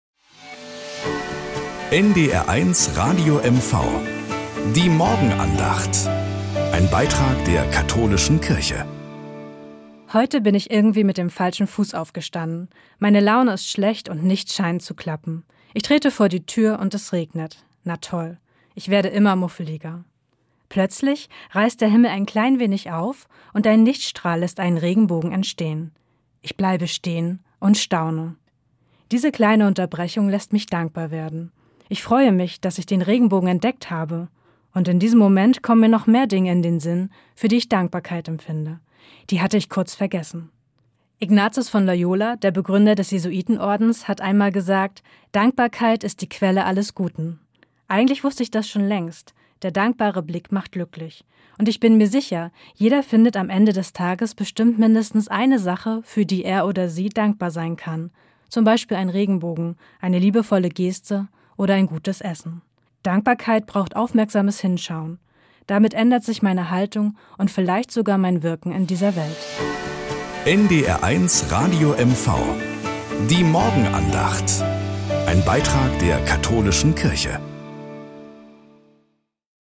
Morgenandacht bei NDR 1 Radio MV
Am Montag auf Plattdeutsch.